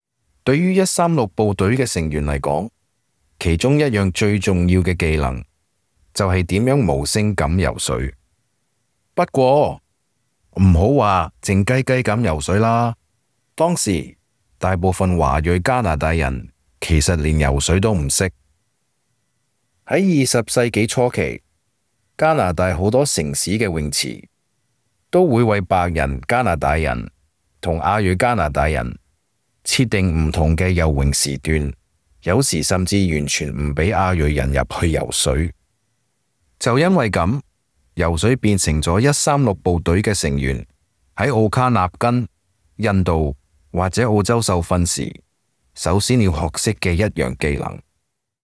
Special Operations Voiceovers
2_CANTO_Swimming_voiceover__eq_.mp3